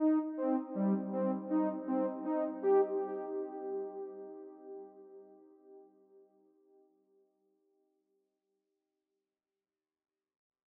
rythmic_pili_arpeggio.wav